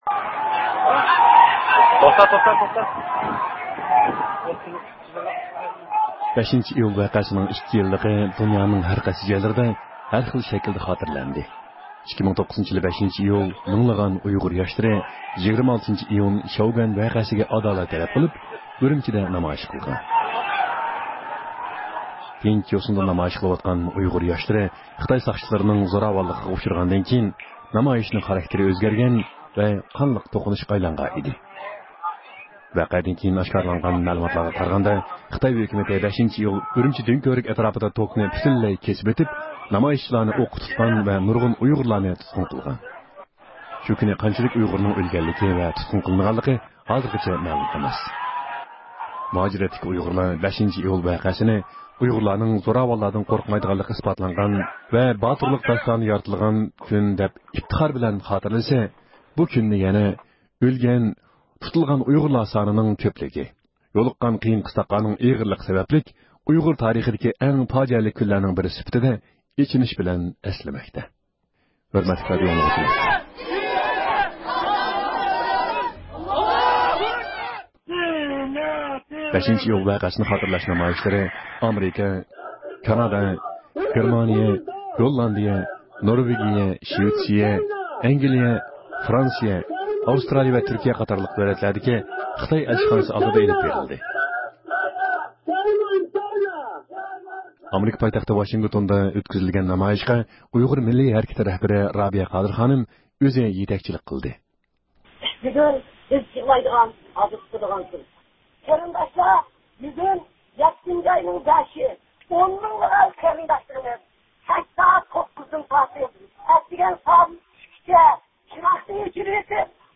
ھەپتىلىك خەۋەرلەر (2-ئىيۇلدىن 8-ئىيۇلغىچە) – ئۇيغۇر مىللى ھەركىتى